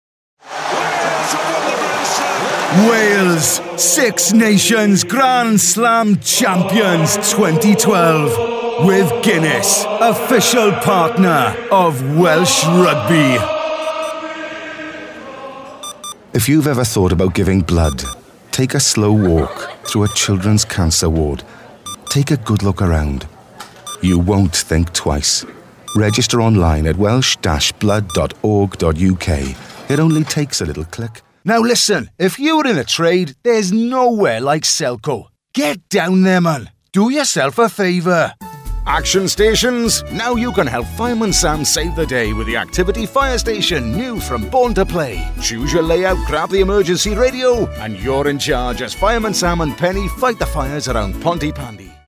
ISDN UK Voiceover. Big range, big voice, Pro since 1994 - fast delivery via MP3.